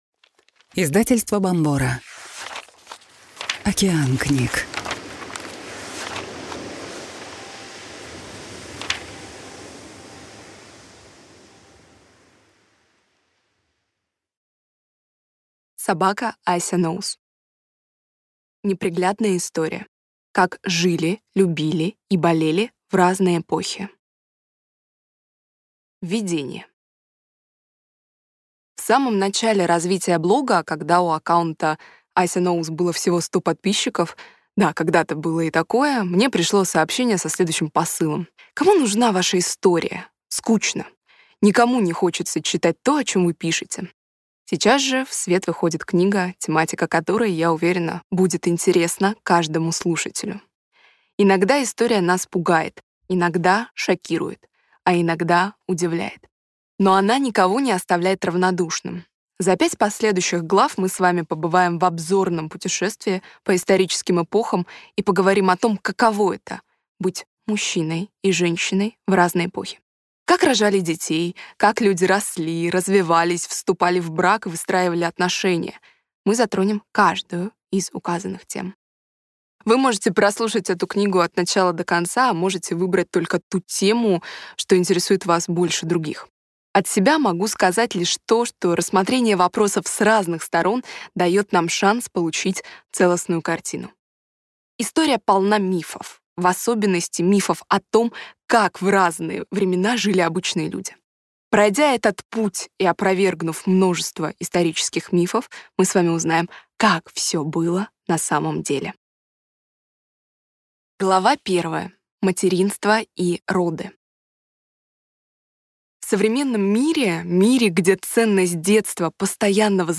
Аудиокнига Неприглядная история. Как жили, любили и болели в разные эпохи | Библиотека аудиокниг